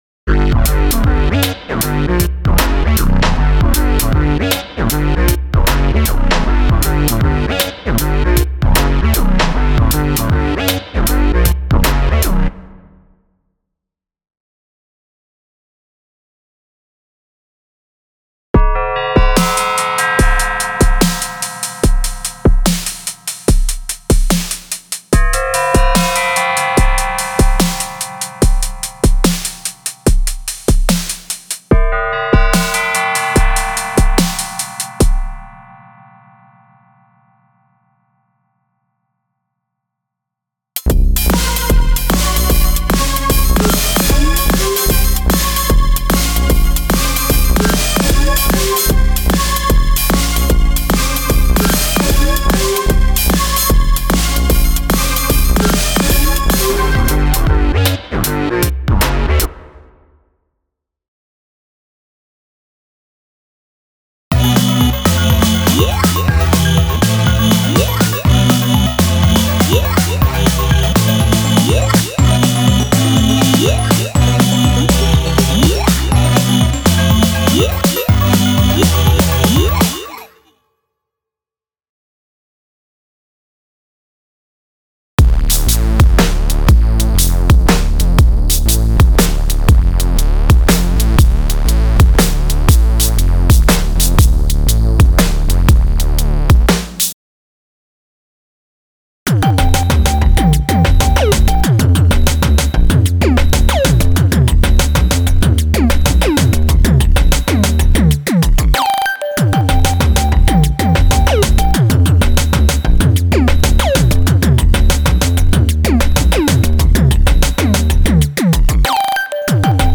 This track was almost fully made on DNII (just the vocals come from DTII).